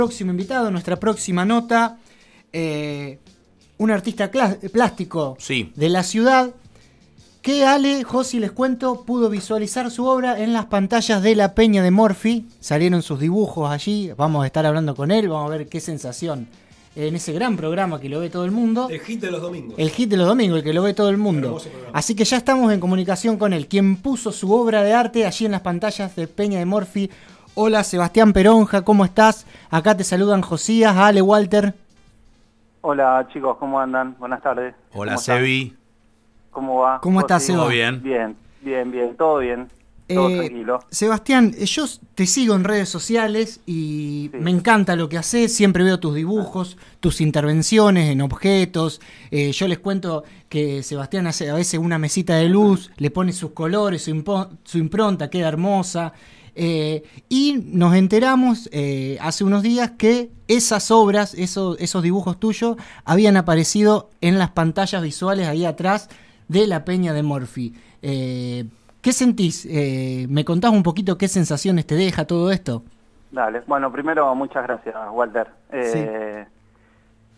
VOCES EN RADIO DEL SUR
Pasó ante los micrófonos de «Sábados Blancos», programa de FM 107.3 – RADIO DEL SUR.